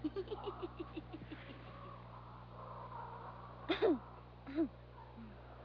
Memorable Dialog
Cha-ka_laughs.wav